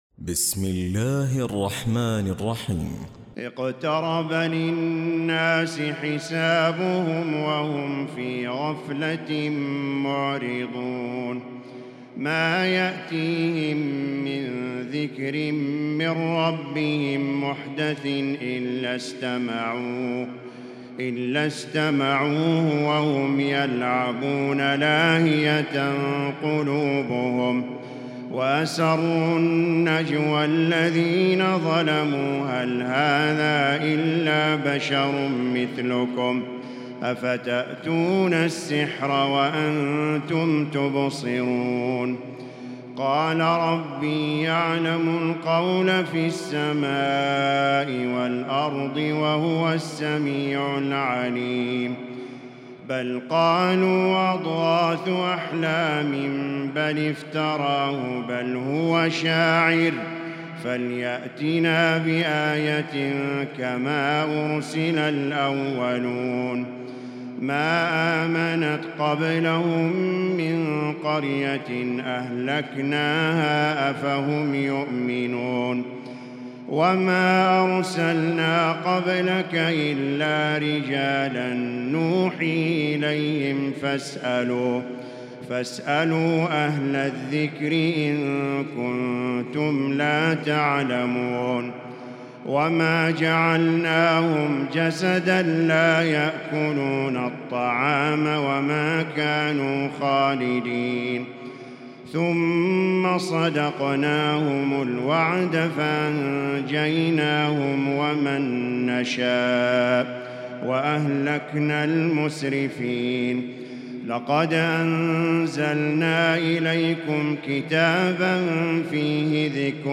تراويح الليلة السادسة عشر رمضان 1438هـ سورة الأنبياء كاملة Taraweeh 16 st night Ramadan 1438H from Surah Al-Anbiyaa > تراويح الحرم المكي عام 1438 🕋 > التراويح - تلاوات الحرمين